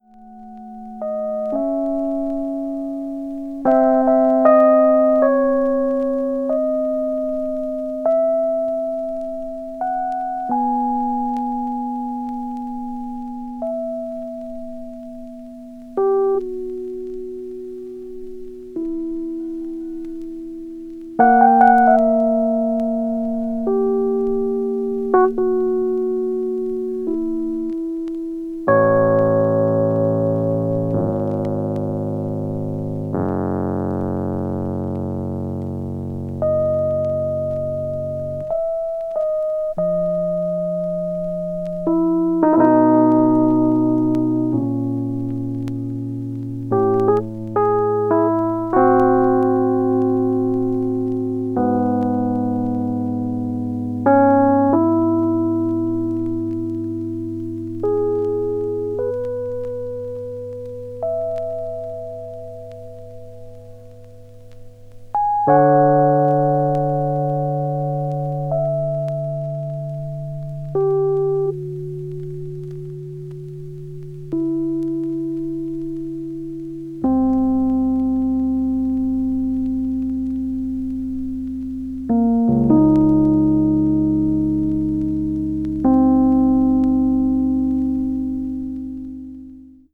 avant-jazz   contemporary jazz   deep jazz   free jazz